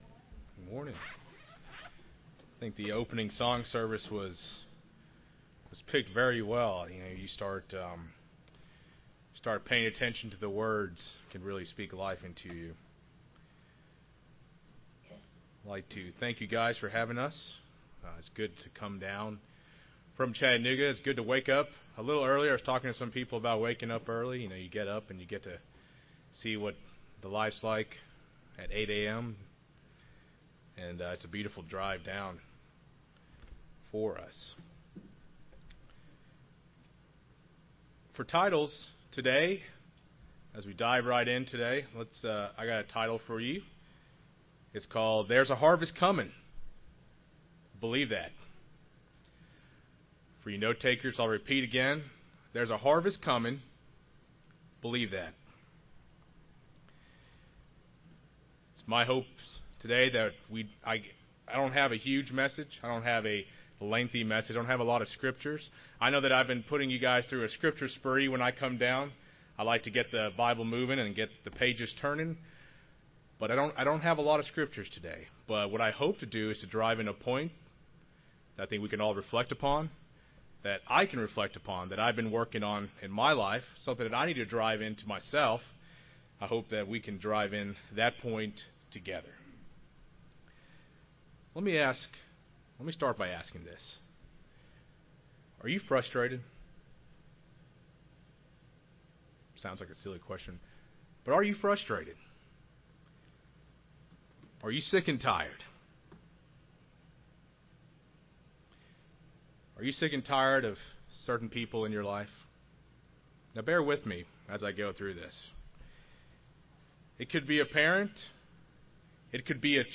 Given in Rome, GA
UCG Sermon